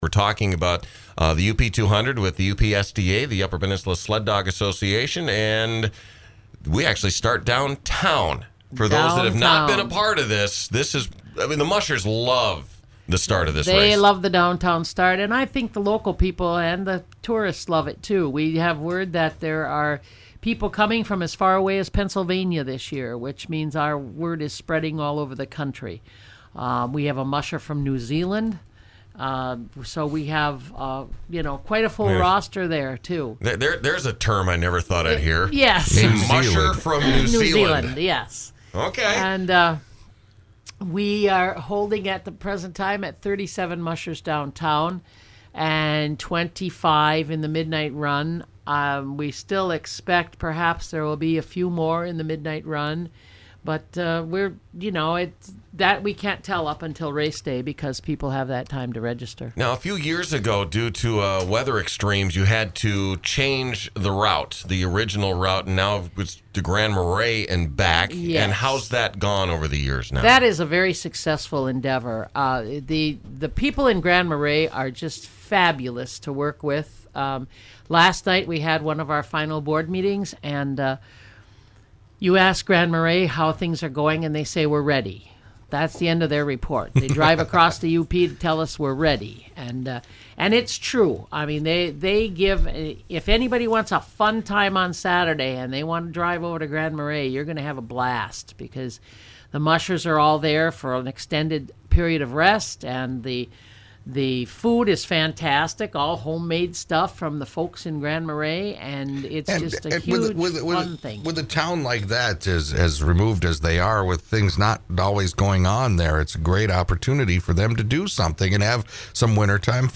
COMMUNITY INTERVIEW